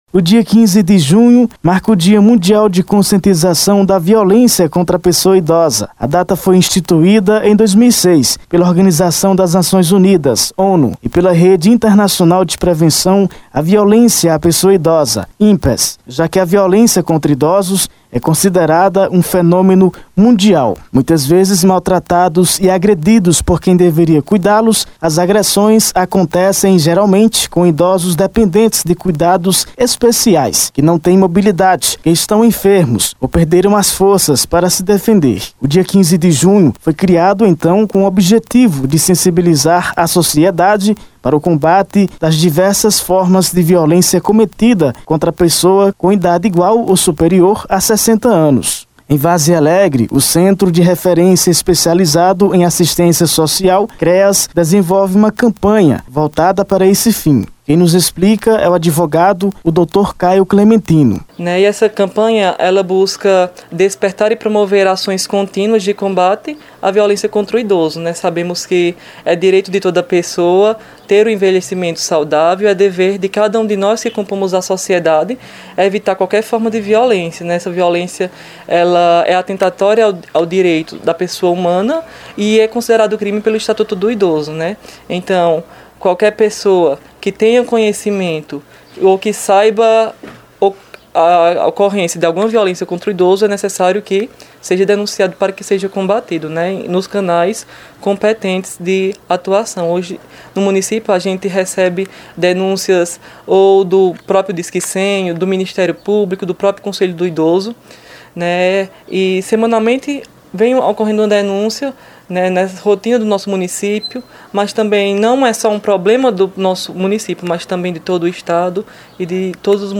Confira a reportagem completa feita para o Jornal Cultura [FM 96.3] para o Dia Internacional de Combate à Violência contra a Pessoa Idosa: